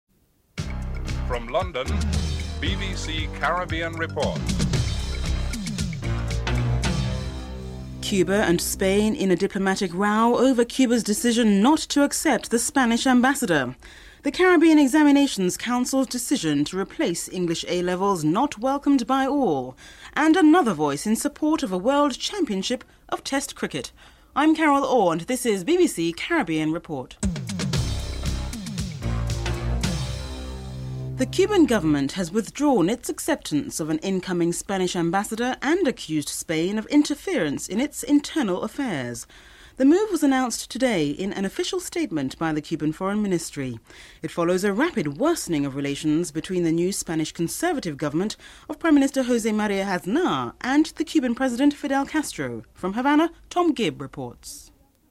1. Headlines (00:00-00:29)